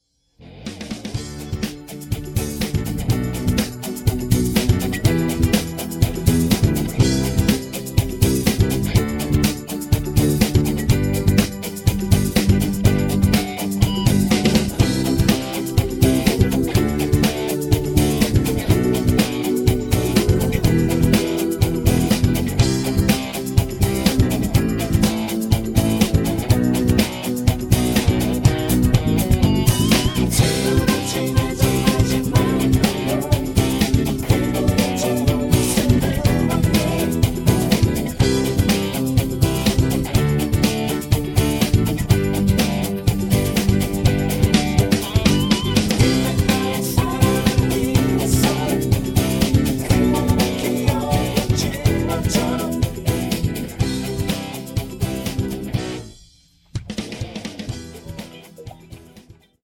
음정 -1키 3:28
장르 가요 구분 Voice MR